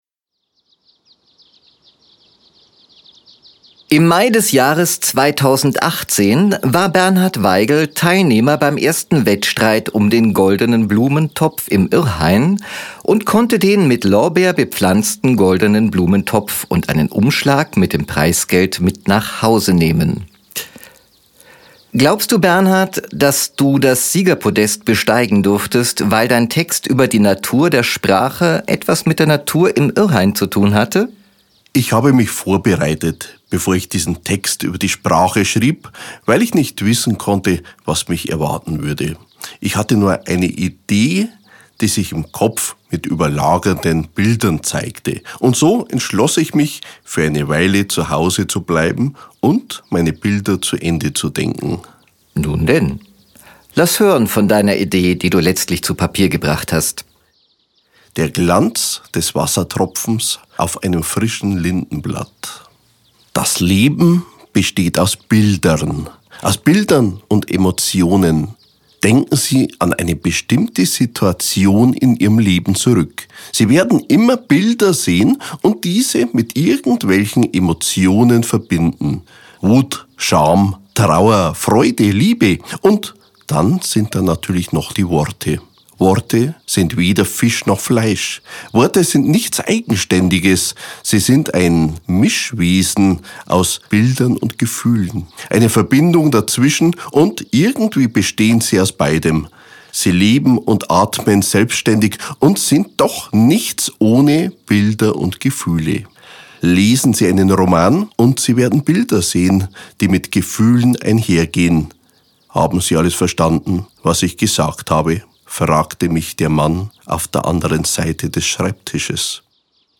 Diese kurzen Hörspiele (max. 10 Minuten) sind von Mitgliedern des Blumenordens, die in den zwanziger Jahren dieses Jahrhunderts leben oder noch gelebt haben, geschrieben und gesprochen, gespielt oder musikalisch vorgetragen.